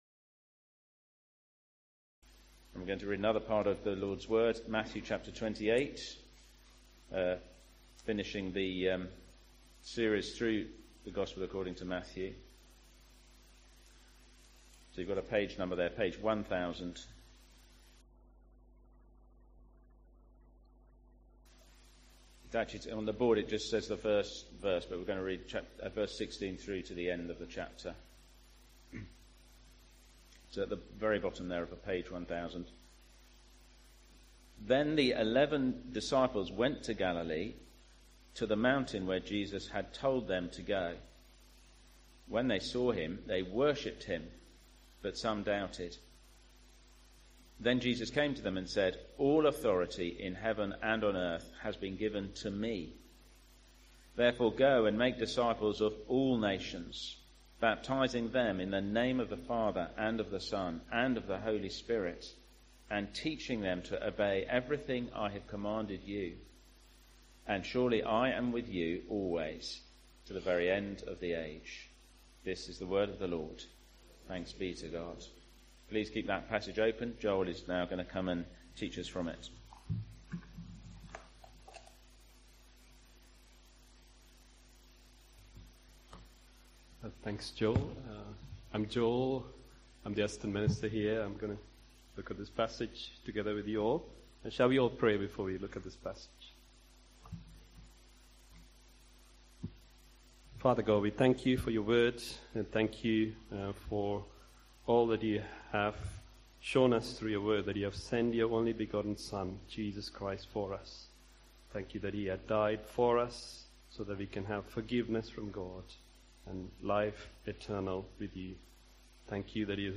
Sermons – Dagenham Parish Church
Service Type: Sunday Morning